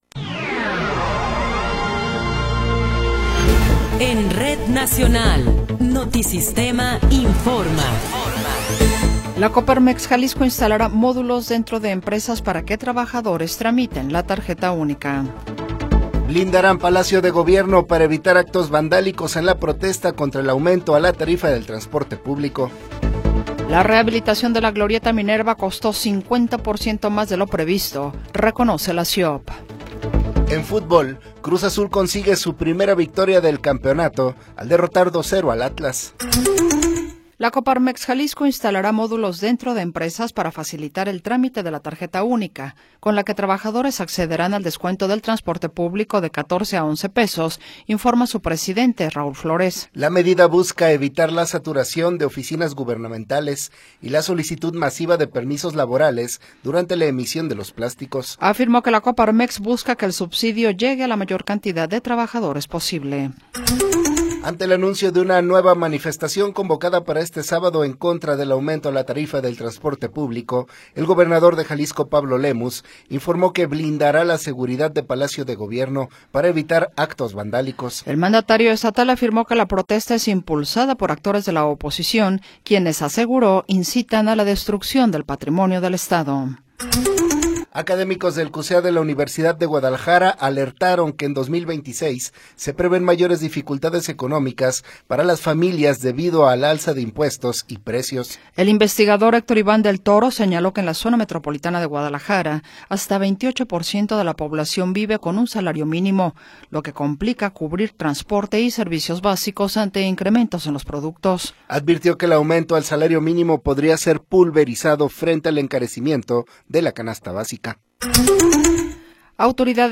Noticiero 20 hrs. – 14 de Enero de 2026